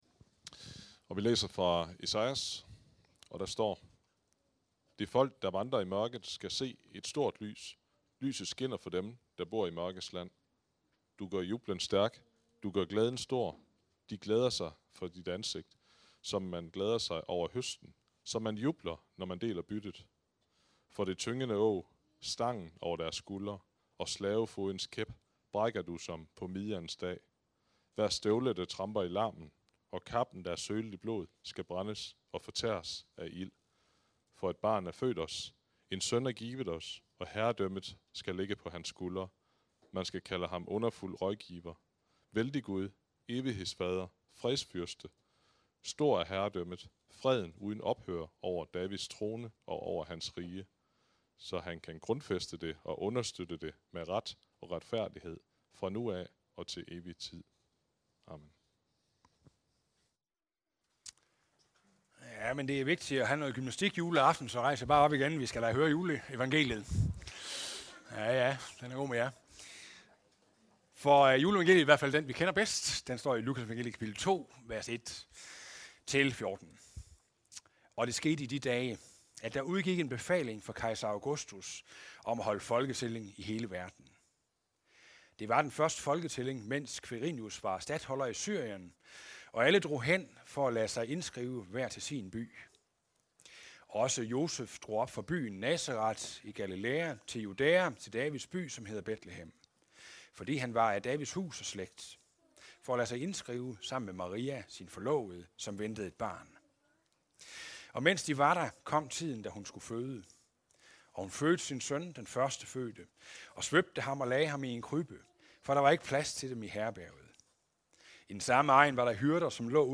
Juleaften – Prædiken – “Gaven Jesus, gir og gir!”